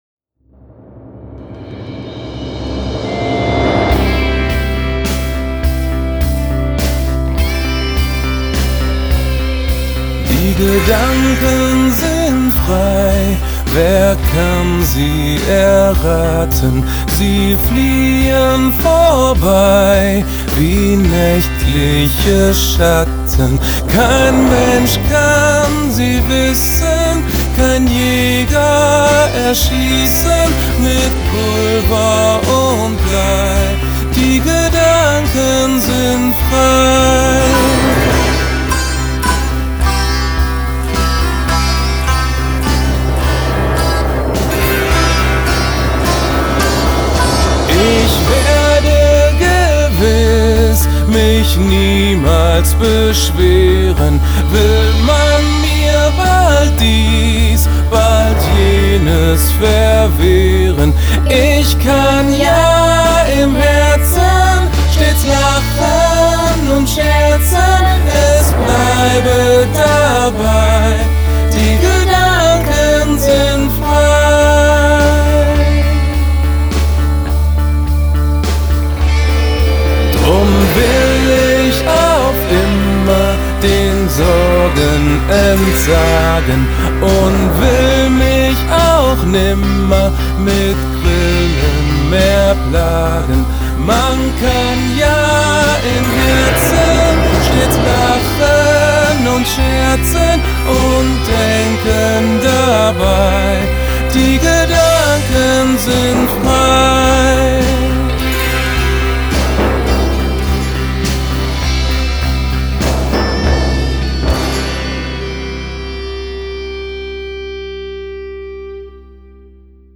Traditionelle Lieder